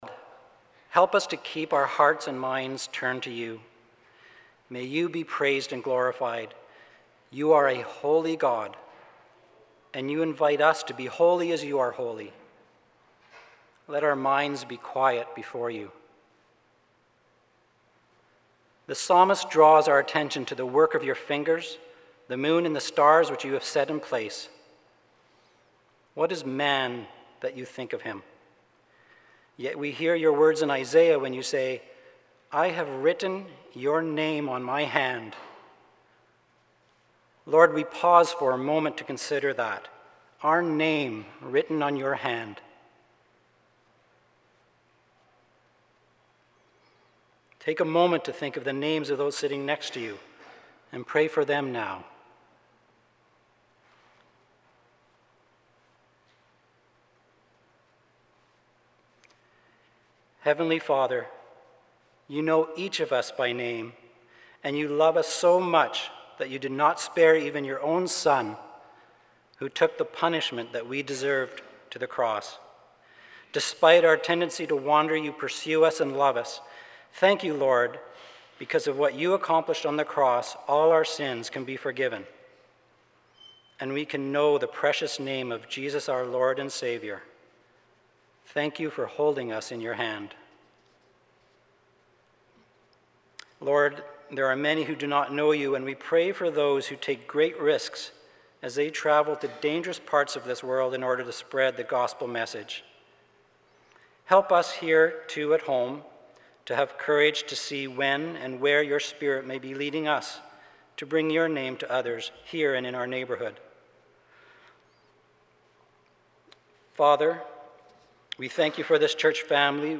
Sermons | Langley Immanuel Christian Reformed Church